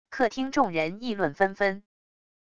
客厅众人议论纷纷wav音频